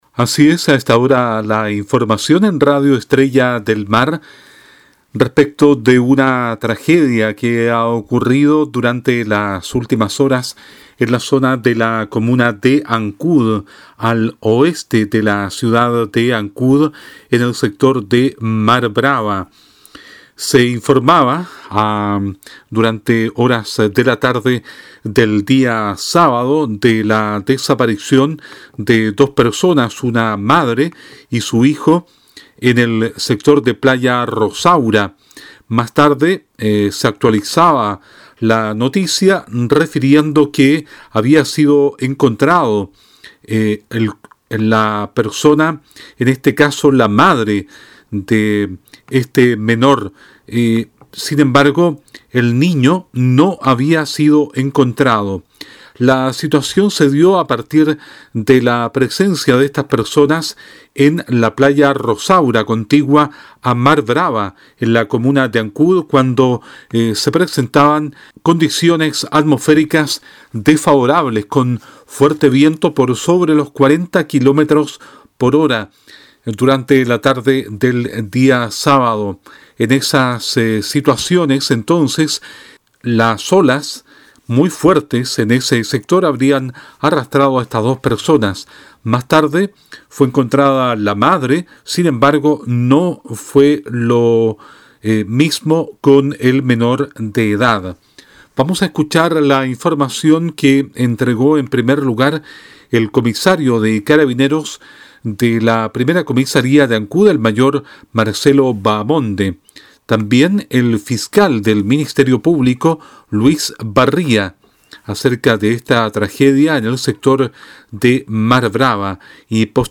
Habla el Comisario de Carabineros de la Primera Comisaria de Ancud Mayor Marcelo Bahamonde, el Fiscal del Ministerio Público Luis Barria y el Gobernador Marítimo de Castro Claudio Vidal
DESPACHO-ANCUD-TRAGEDIA-SECTOR-MAR-BRAVA.mp3